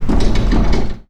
rattle6.wav